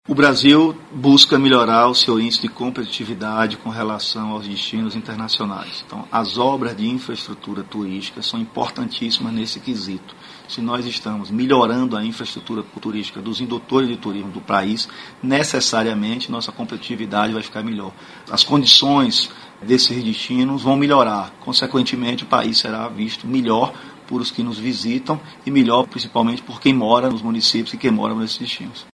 aqui para ouvir declaração do secretário Fábio Mota sobre a importância da realização das obras.